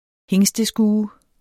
Udtale [ ˈheŋsdəˌsguːə ]